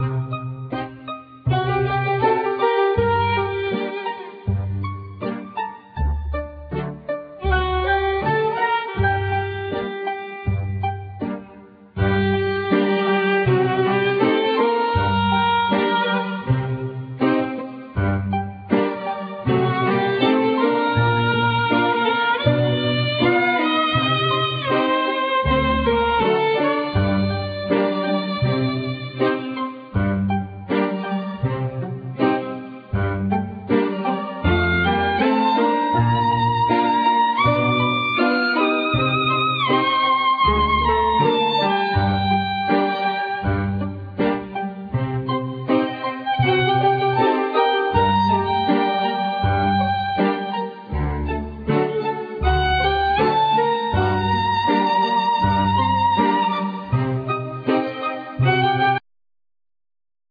Accordion,Clarinet,Piano,Percussion,Computer
Violin
Doudouk
Voice
Viola
Cello
Double Bass